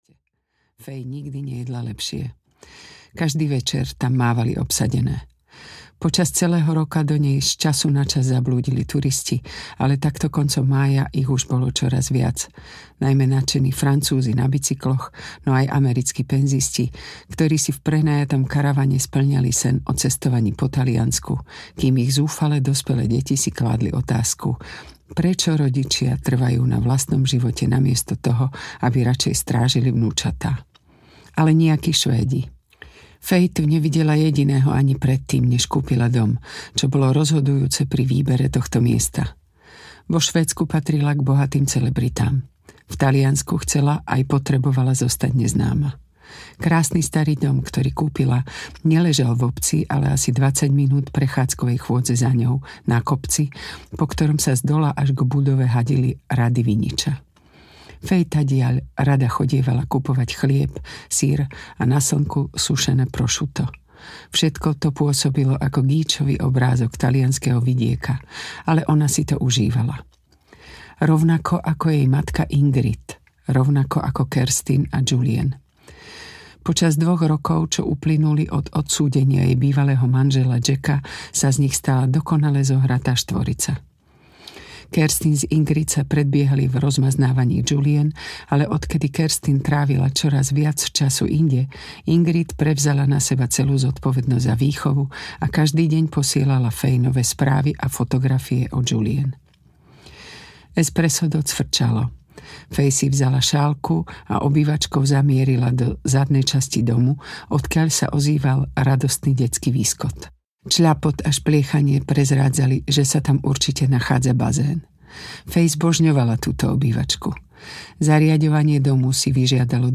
Strieborné krídla audiokniha
Ukázka z knihy